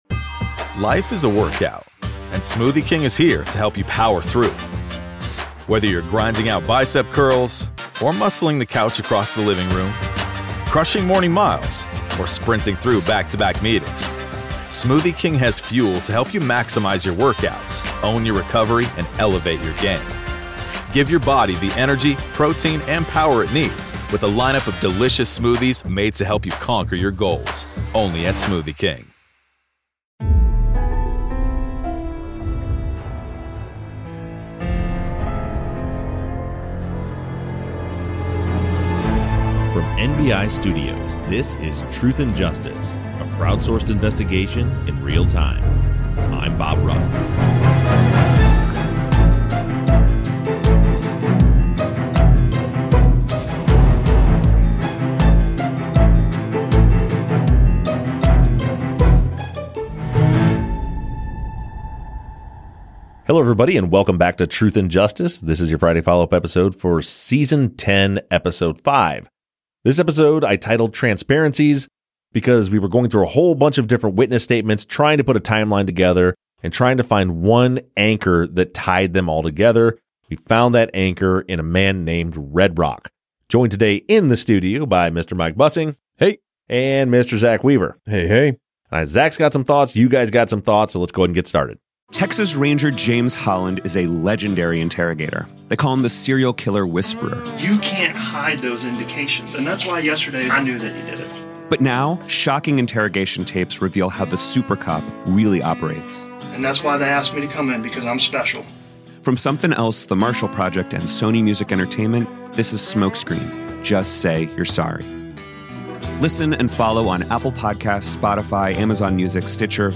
The guys discuss listener questions from social media